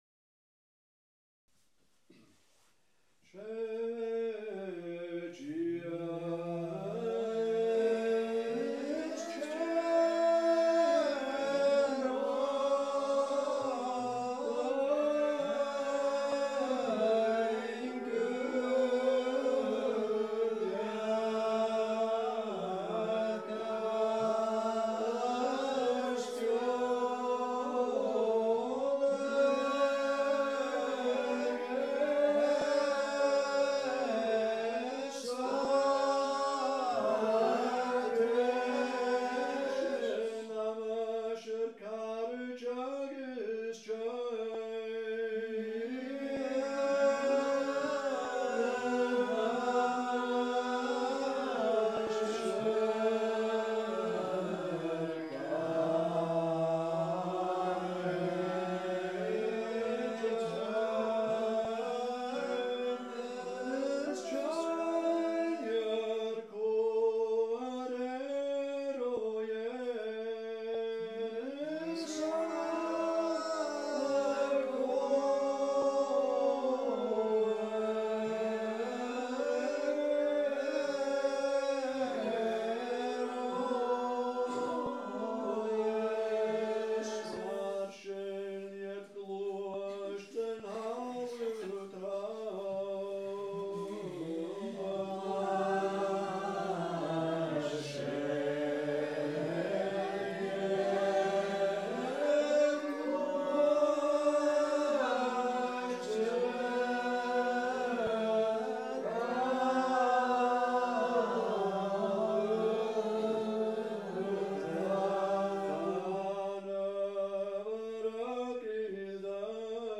Gaelic Psalmody - class 4
A’ Seinn nan Sailm Gaelic Psalmody